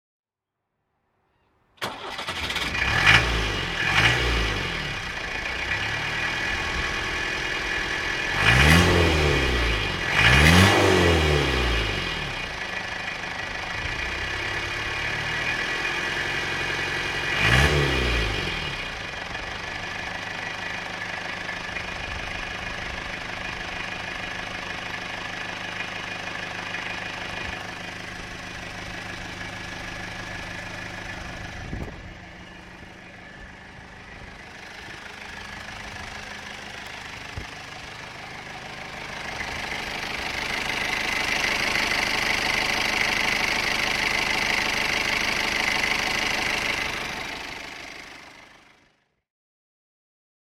Peugeot 403 BD (1965) - Starten und Leerlauf (und Tour um's Auto)